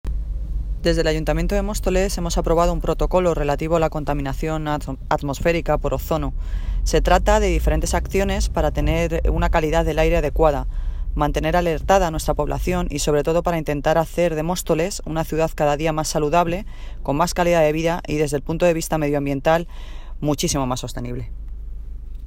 Audio - Noelia Posse (Alcaldesa de Móstoles) Sobre Protocolo Ozono